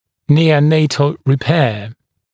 [ˌniːə’neɪtl rɪ’peə][ˌни:э’нэйтл ри’пэа]неонатальное восстановление (о расщелине губы и/или нёба)